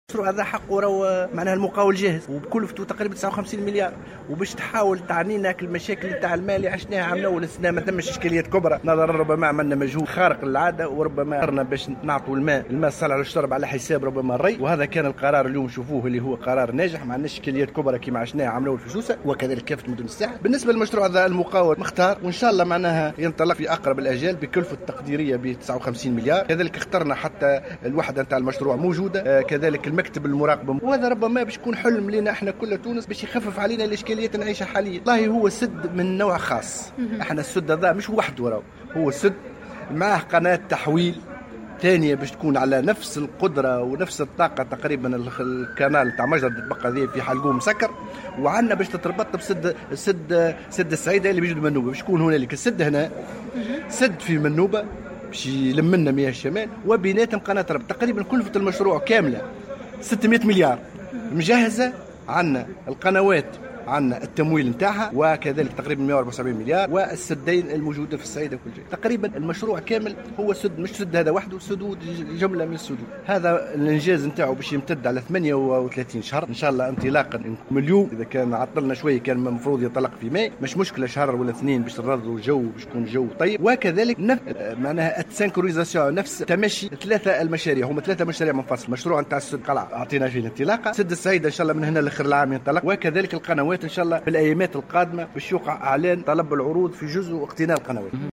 واعتبر كاتب الدولة، في تصريح للجوهرة أف أم، اليوم الثلاثاء، على هامش زيارة أداها إلى ولاية سوسة، عاين خلالها موقع السد، أن هذا المشروع الذي تبلغ كلفته 59 مليون دينار، يشكل "حلما" للتونسيين، نظرا لمساهمته المنتظرة في تخفيف الإشكاليات التي تشهدها البلاد على مستوى الموارد المائية.